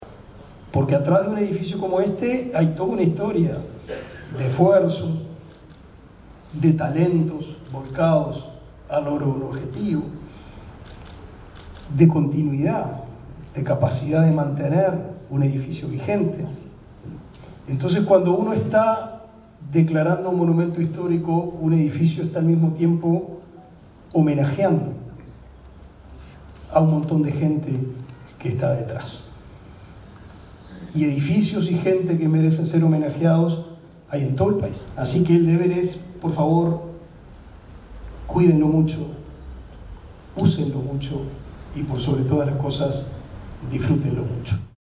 El Complejo Cultural Politeama – Teatro Atahualpa del Cioppo fue declarado Monumento Histórico Nacional
pablo_da_silveira_ministro_de_educacion_y_cultura.mp3